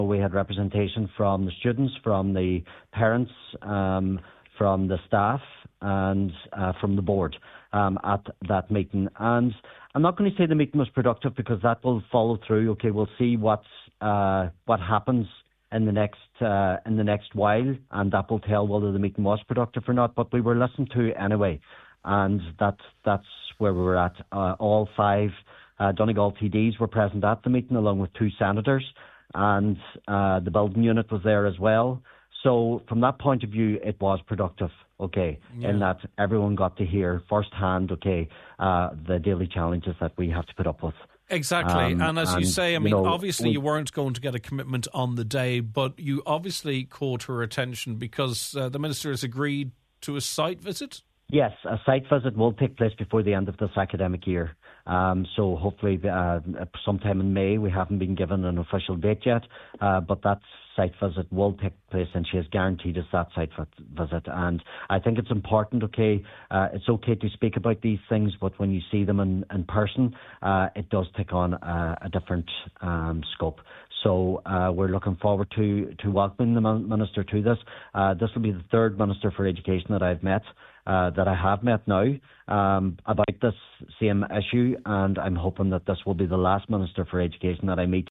Highland Radio